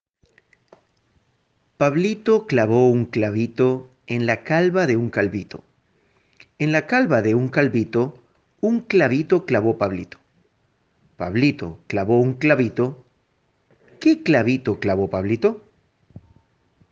Jazykolam
Výslovnost: [Pablito klavó un klavito en la kalva de un kalvito. En la kalva de un kalvito, un klavito klavó Pablito. Pablito klavó un klavito, ké klavito klavó Pablito]